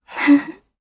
Isolet-Vox-Laugh_jp.wav